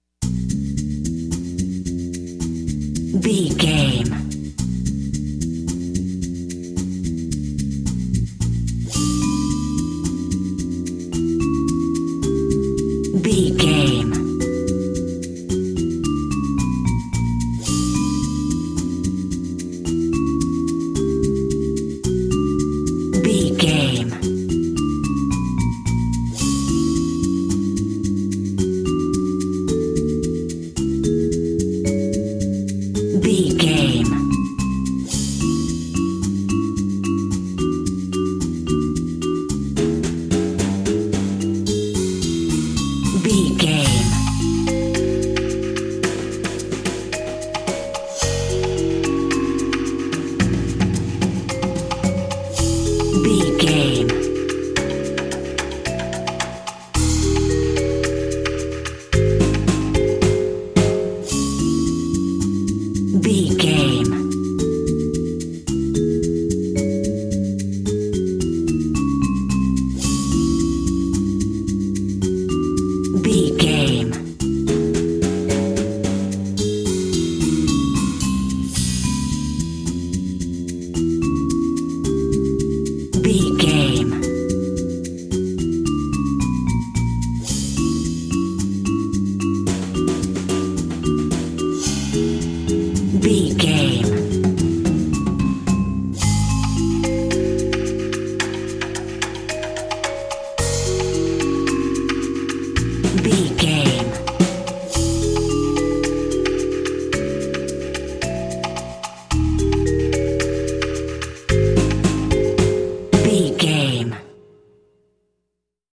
Aeolian/Minor
C#
percussion
flute
bass guitar
silly
circus
goofy
comical
cheerful
perky
Light hearted
sneaking around
quirky